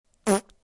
Download Fart Noises sound effect for free.
Fart Noises